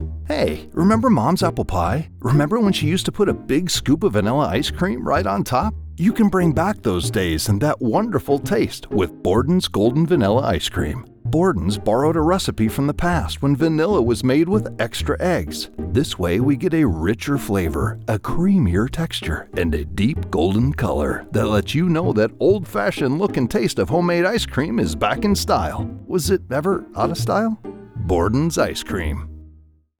My voice has been described as rich, articulate, and engaging.
Conversational Ice Cream Commercial
English - Midwestern U.S. English
I use a Sennheiser MKH 416 mic in a custom home studio.